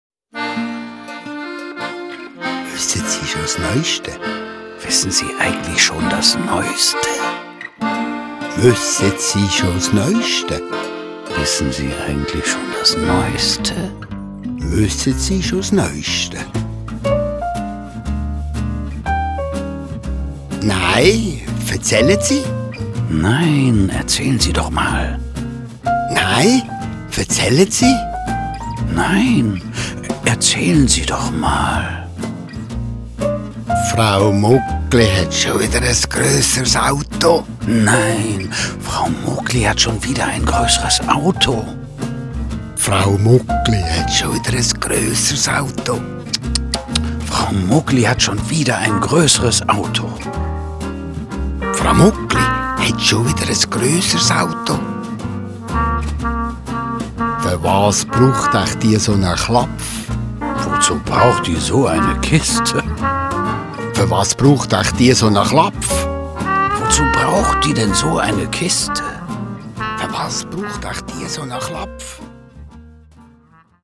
Schwyzerdütsch mit Emil Steinberger - Schweizer Dialekt verknüpft mit coolen Pop & Jazz Grooves.
Der bekannte Kabarettist Emil Steinberger gibt einen amüsanten Einblick ins Schwyzerdütsche - ob beim Schwätzchen mit dem Züricher Taxifahrer oder beim Klatsch und Tratsch über die Nachbarn. Verknüpft mit coolen Pop & Jazz Grooves und verpackt in Dialogen stellt Emil Steinberger den Schweizer Dialekt, Redewendungen und Lebensart vor.